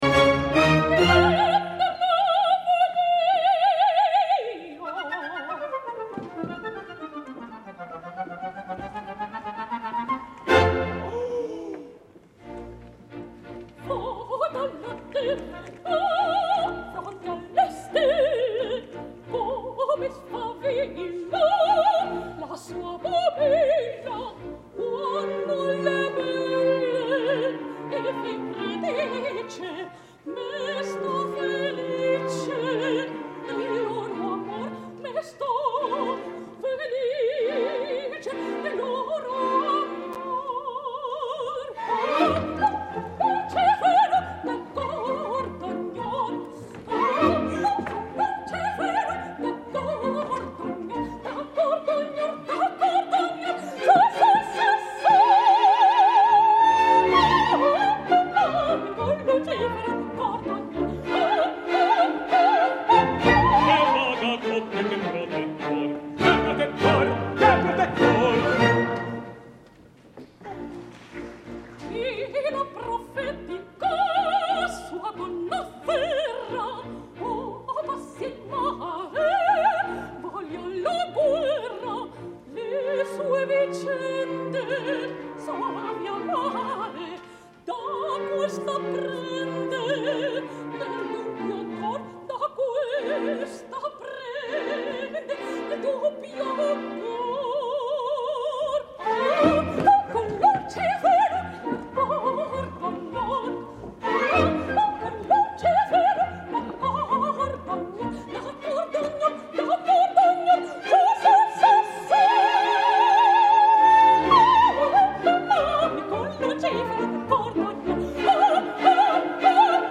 soprano valenciana